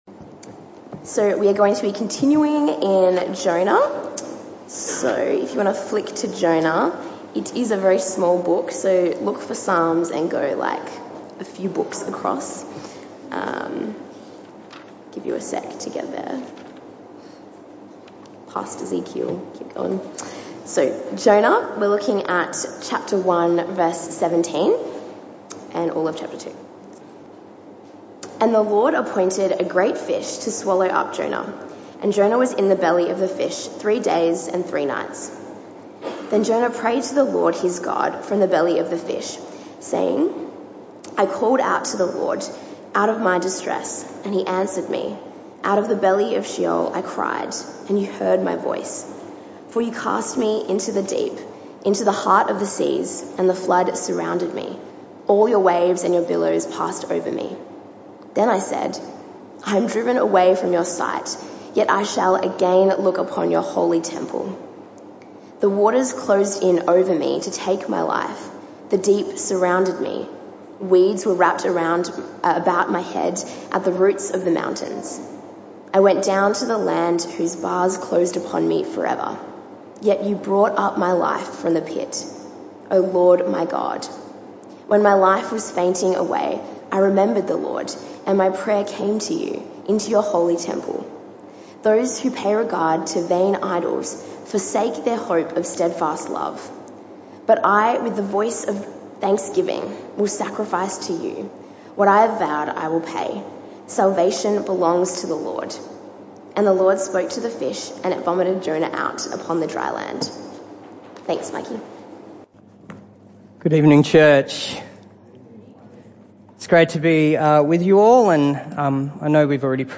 This talk was part of the PM Service series entitled Jonah & The Lord.
Jonah 2 Service Type: Evening Service This talk was part of the PM Service series entitled Jonah & The Lord.